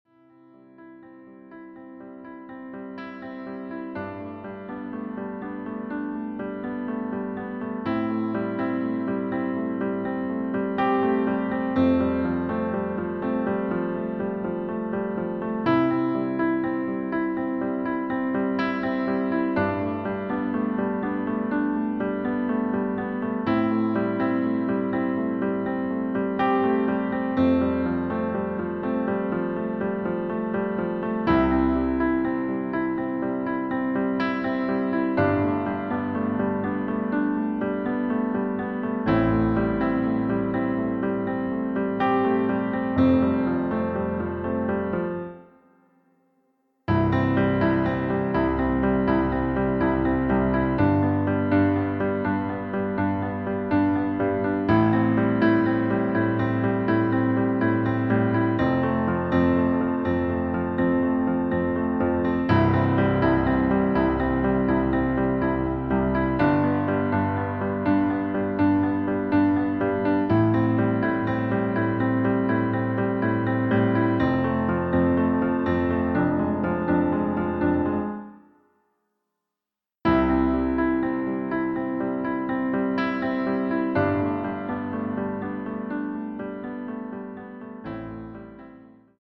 • Tonart: Bb Dur, C Dur, D Dur, E Dur
• Das Instrumental beinhaltet NICHT die Leadstimme
Klavier / Streicher